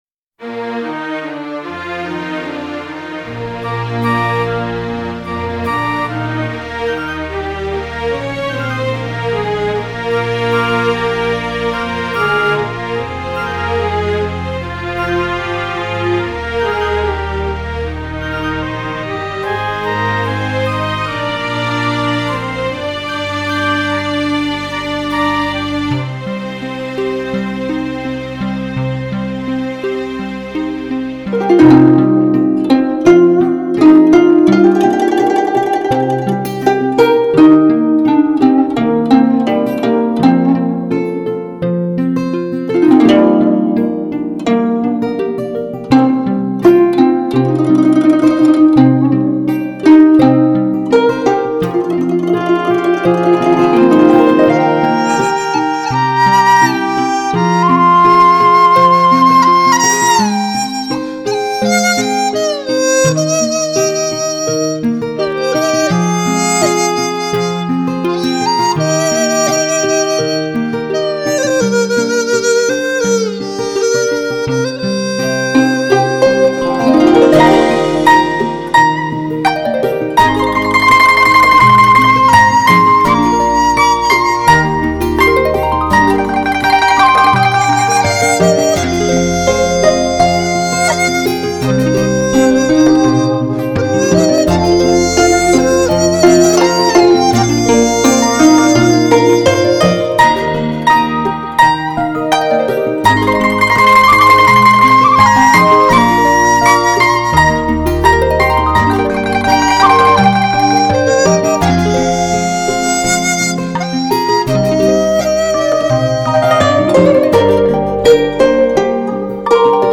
2周前 纯音乐 8
• 01.美丽的金孔雀(葫芦丝)
• 02.听妈妈讲那过去的事情(弦乐二重奏）